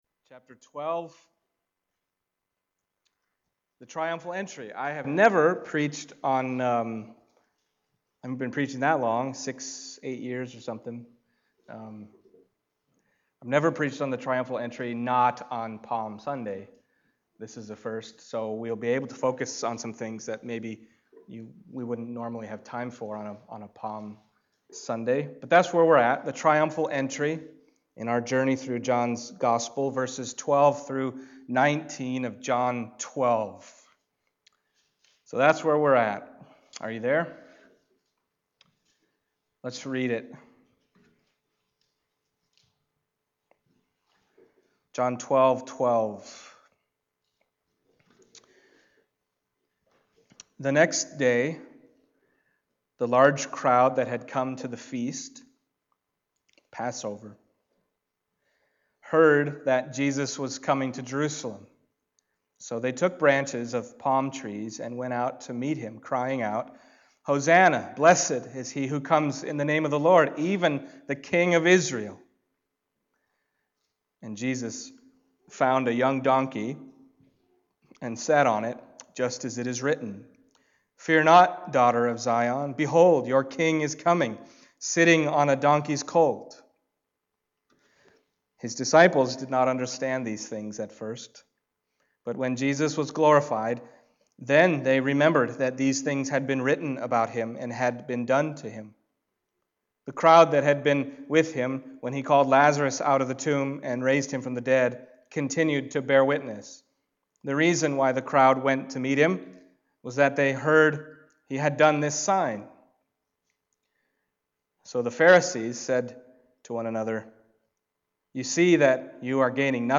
John Passage: John 12:12-19 Service Type: Sunday Morning John 12:12-19 « Loving Jesus Extravagantly The Way Is Hard …